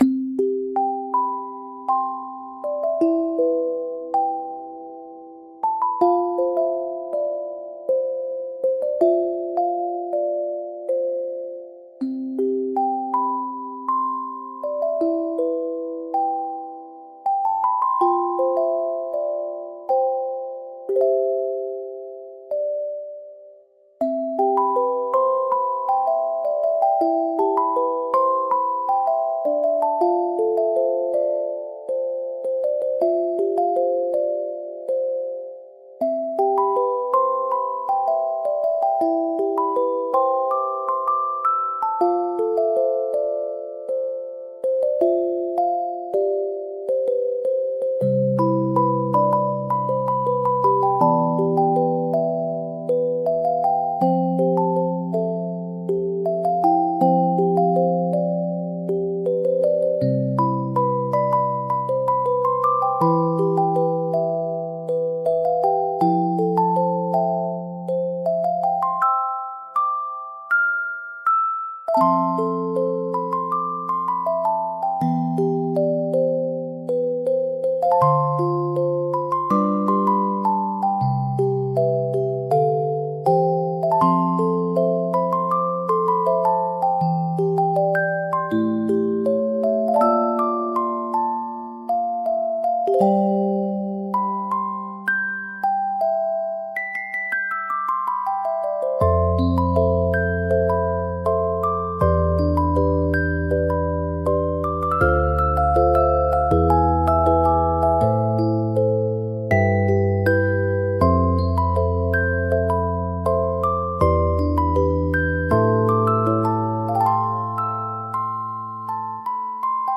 ゆっくり眠れるBGM